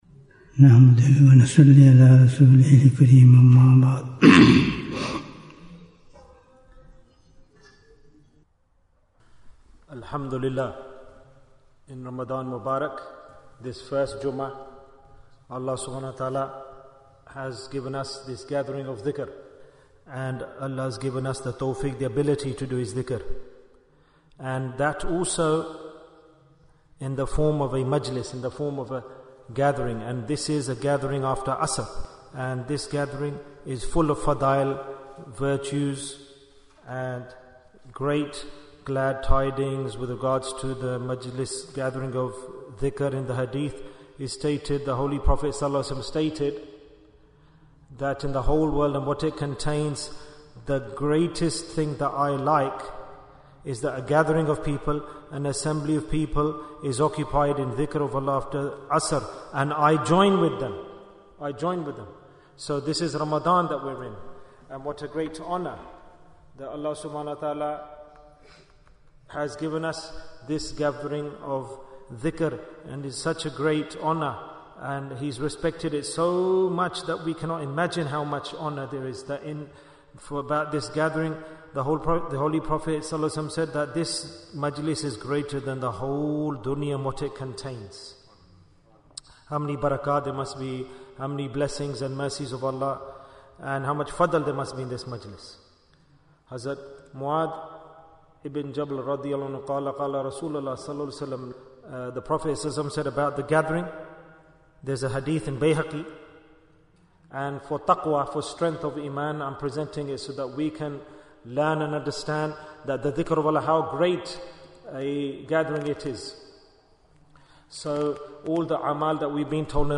Jewels of Ramadhan 2025 - Episode 8 - What is the Reward of Dhikr in Ramadhan? Bayan, 26 minutes7th March, 2025